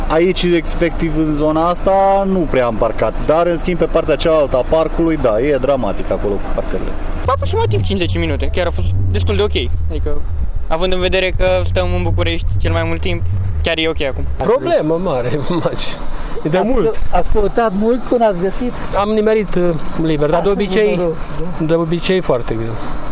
VOXURI-PARCARI-CENTRUL-VECHI.mp3